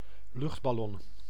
Ääntäminen
IPA: [ba.lɔ̃]